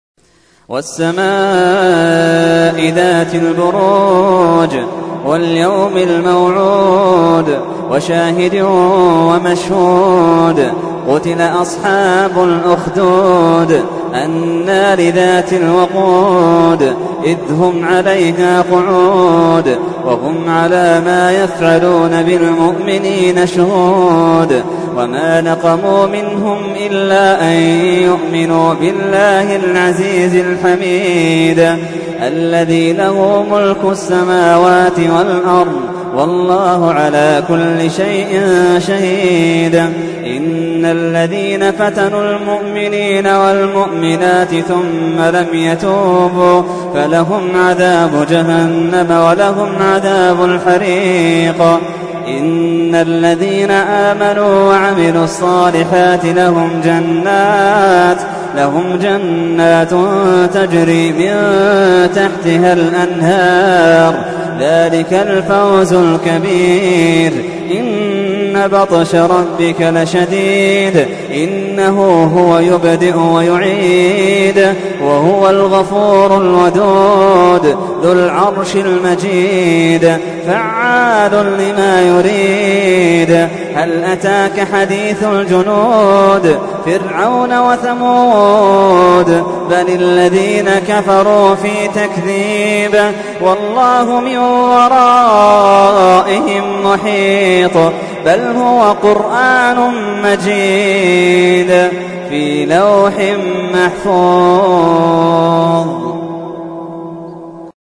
تحميل : 85. سورة البروج / القارئ محمد اللحيدان / القرآن الكريم / موقع يا حسين